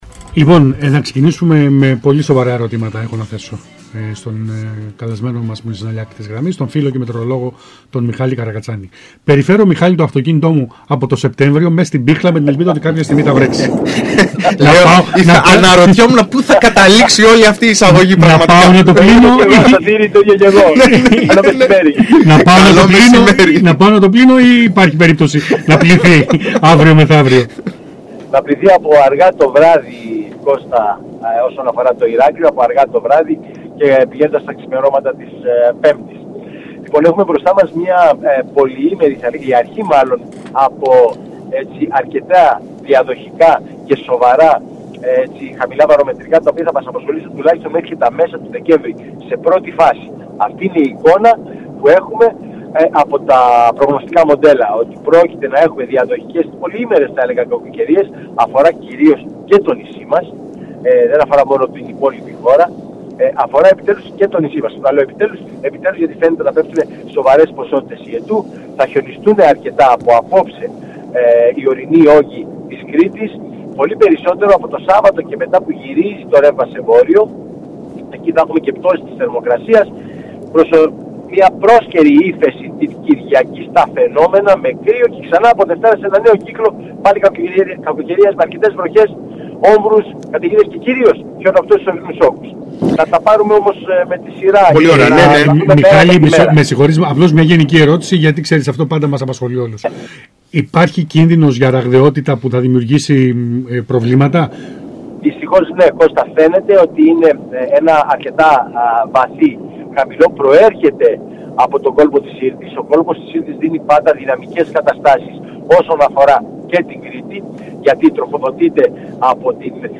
ο οποίος μίλησε στο ΣΚΑΙ Κρήτης 92,1